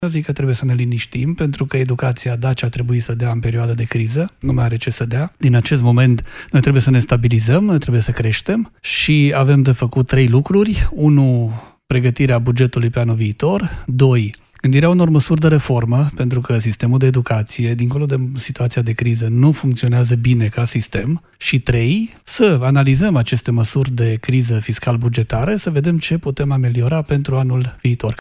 Prezent la emisiunea Academia Europa FM, David a făcut apel la calm.